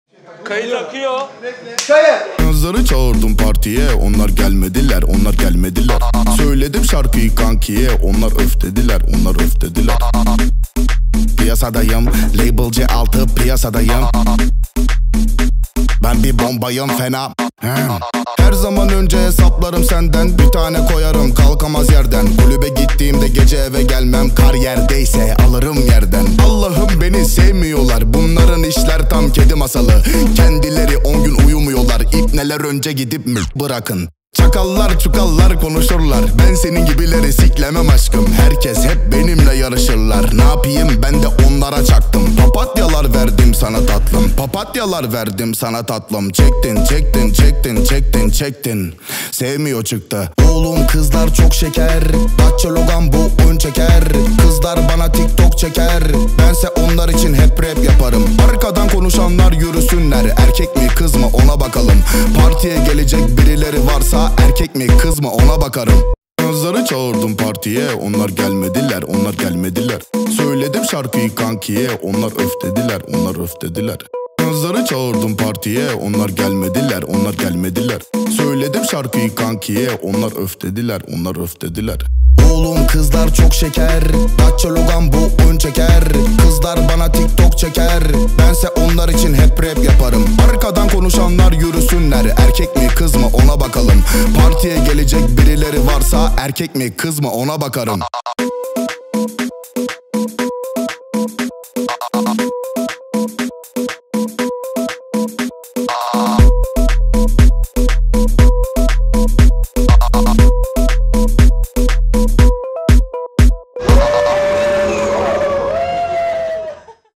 Качество: 320 kbps, stereo
Турецкие песни